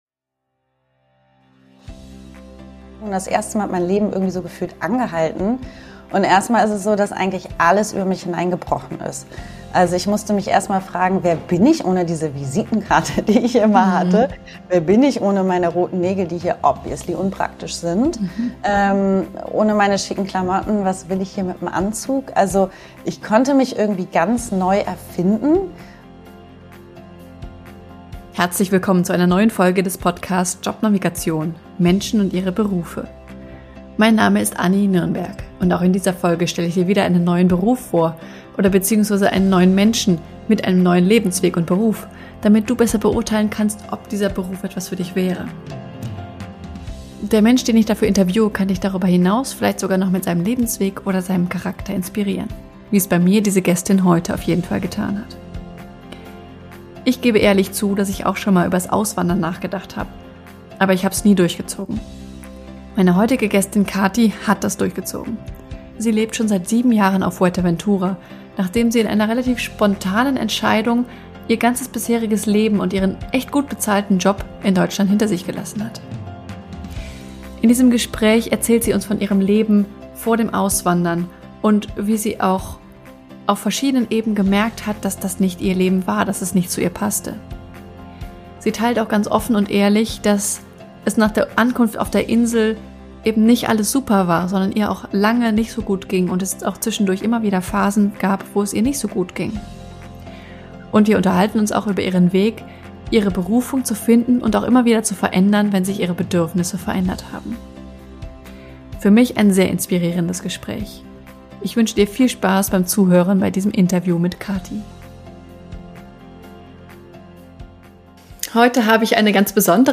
Ein sehr inspirierendes Gespräch!